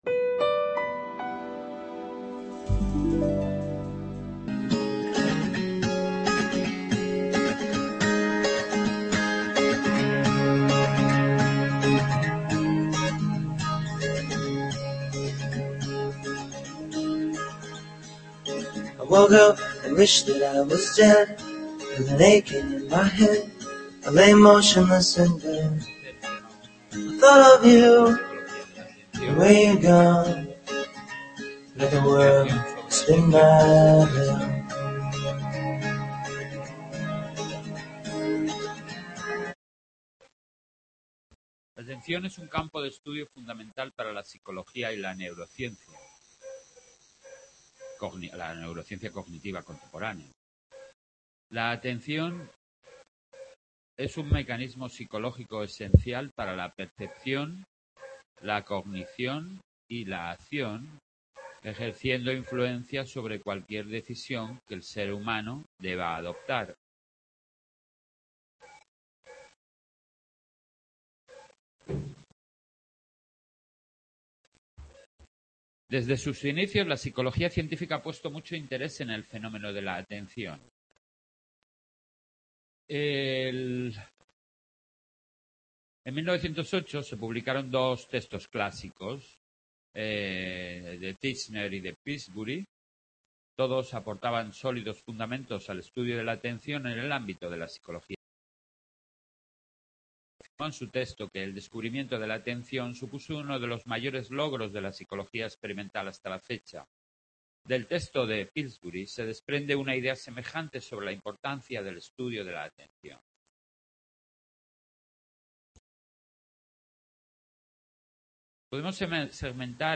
Tema 1 de Psicología de la Atención, grabación realizada en el aula de Sant Boi